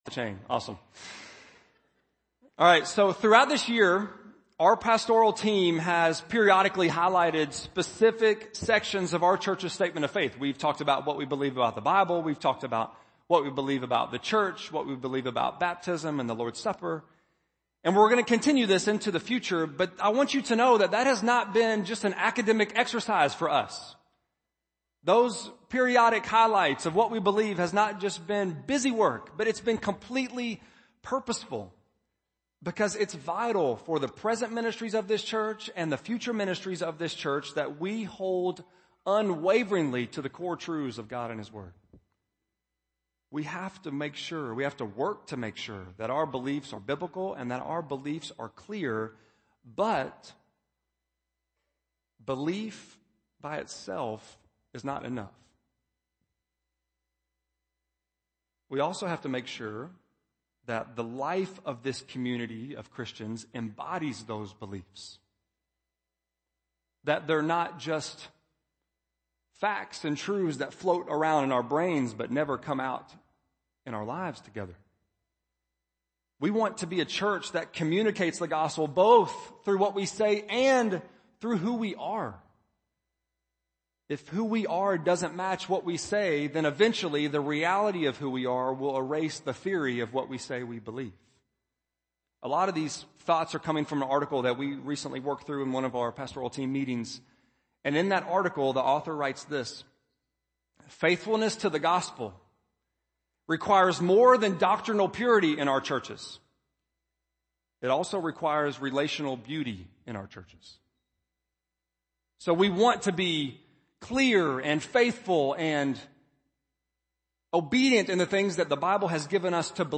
5.23-sermon.mp3